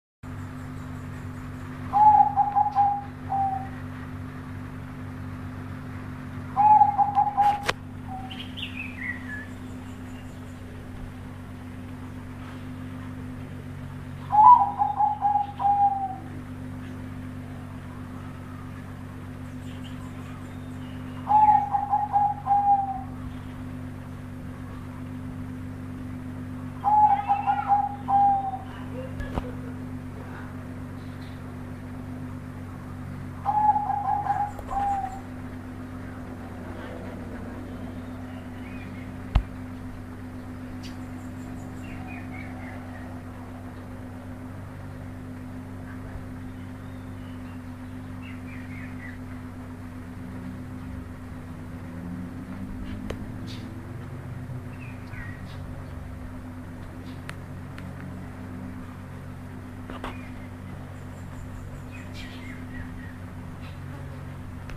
suara-burung-merbuk-b-loudtronix-me-sq.mp3